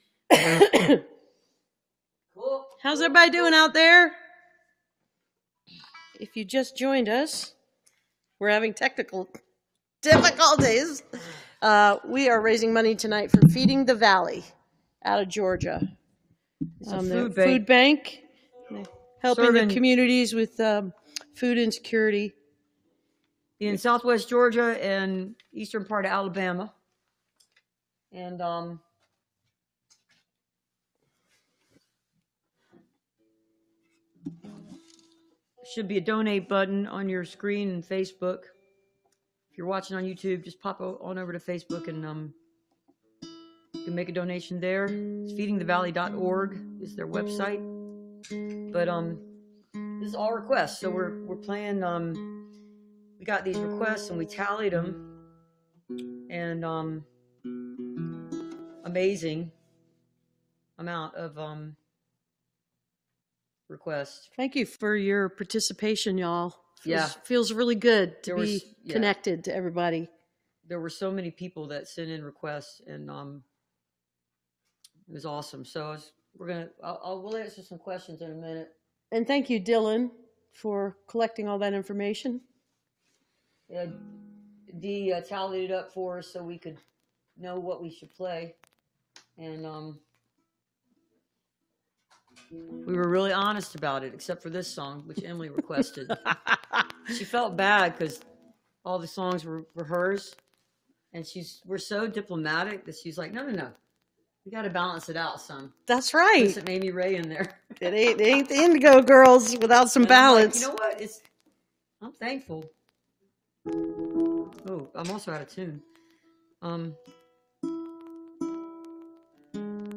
lifeblood: bootlegs: 2020: 2020-05-21: song request livestream - facebook/instagram/youtube (benefit for feeding the valley)
(captured from the youtube video stream)
09. talking with the crowd (2:23)